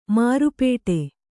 ♪ māru pēṭe